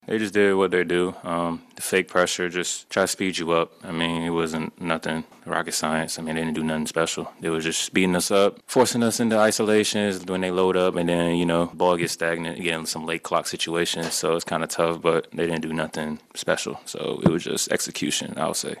Cam Thomas talked about how the Raptors held the Bucks offense in check.